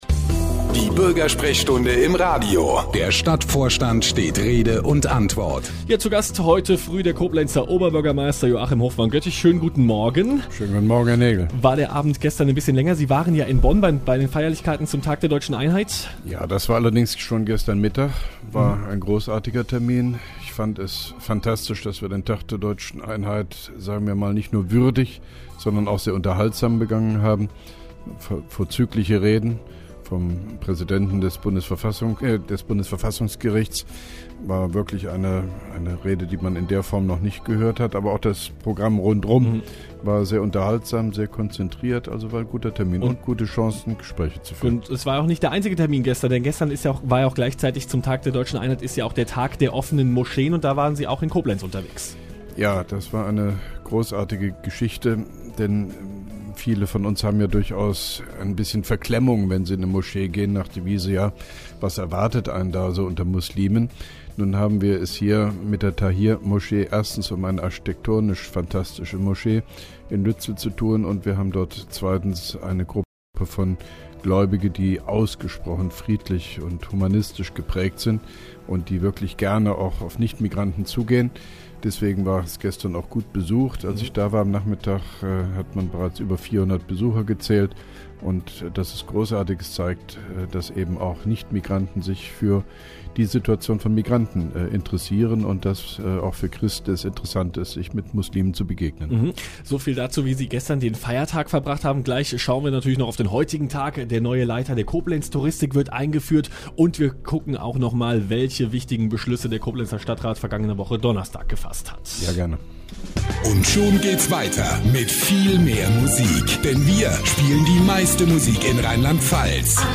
(1) Koblenzer Radio-Bürgersprechstunde mit OB Hofmann-Göttig 04.10.2011